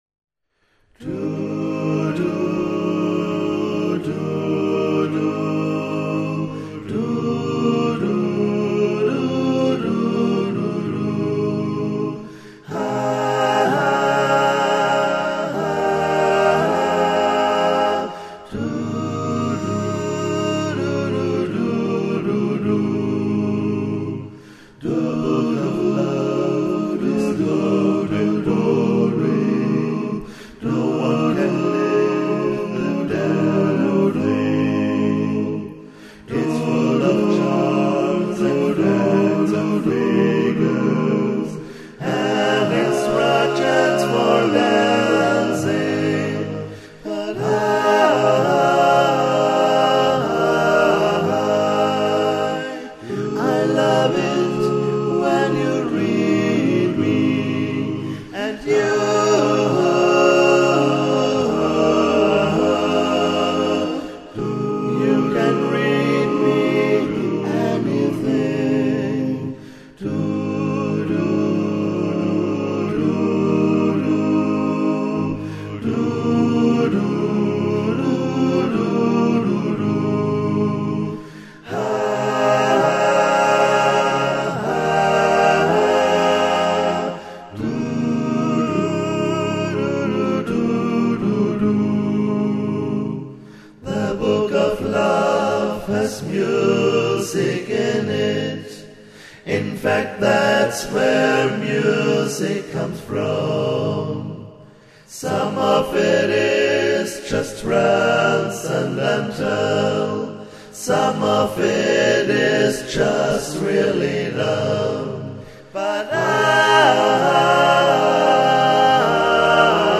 Arrangement für 4-8 st. Männerchor